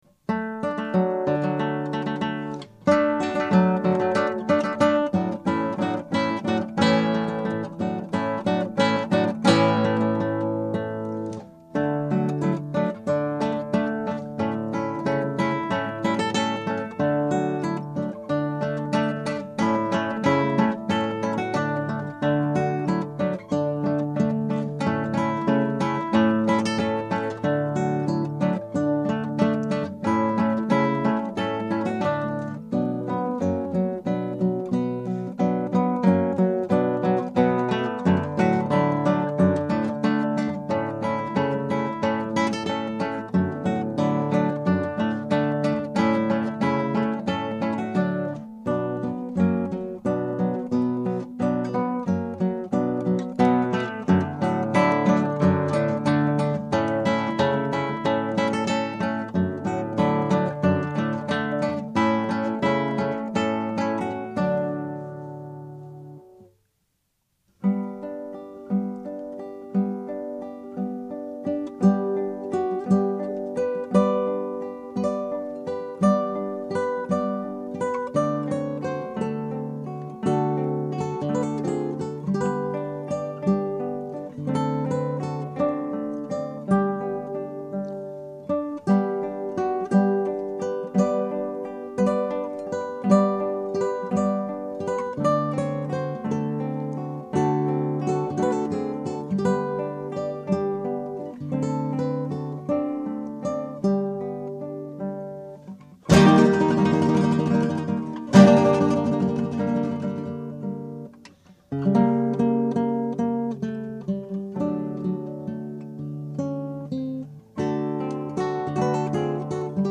Scraps from the Operas arranged for Two Guitars
Scrap 1: Allegro moderato.
Scrap 2 (1:07): Allegro cantabile.
Scrap 3 (2:35): Allegro.